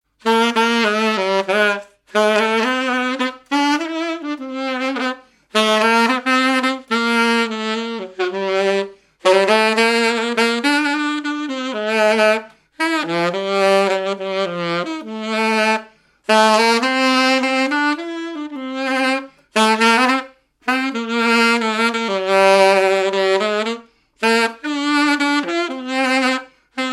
danse : valse
activités et répertoire d'un musicien de noces et de bals
Pièce musicale inédite